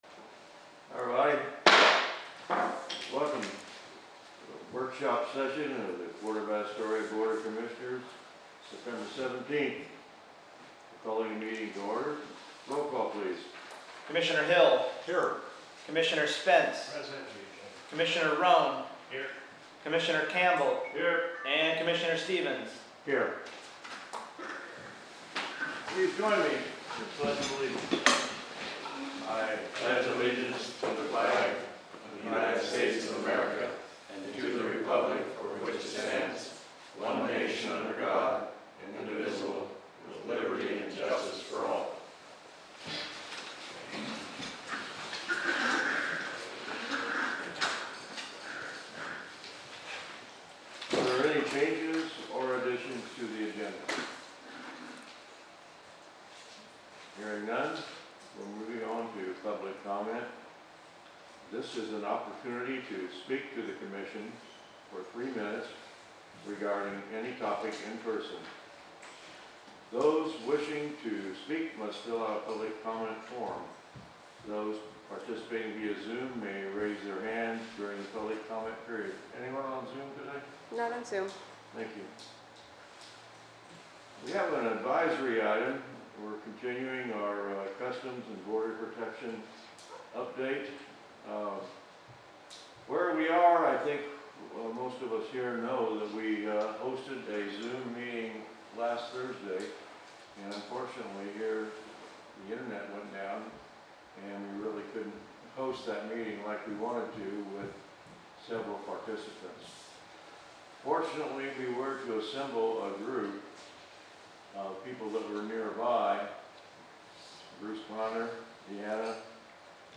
Commission Meeting
422 Gateway Avenue Suite 100, Astoria, OR, at 4 PM